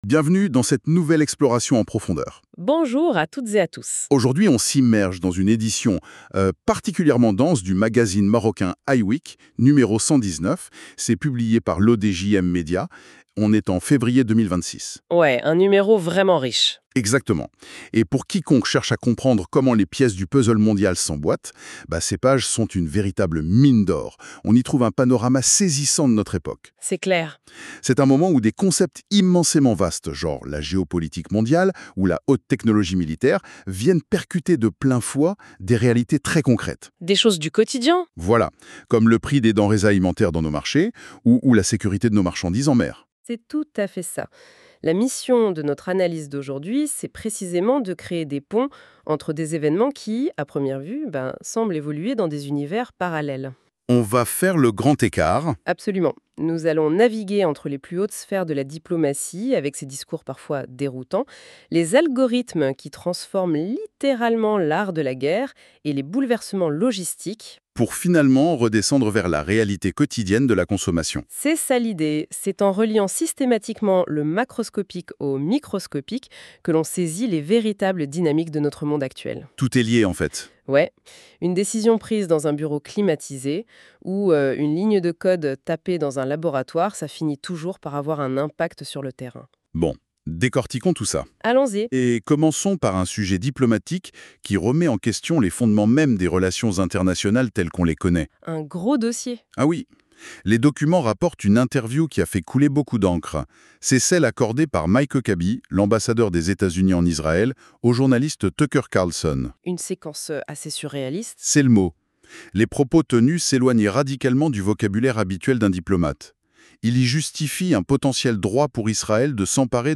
Les débats en podcast des chroniqueurs de la Web Radio R212 débattent de différents sujets d'actualité